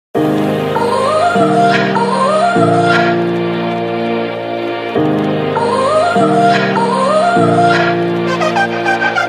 • Качество: высокое
Звук твоей мамки мемный прикол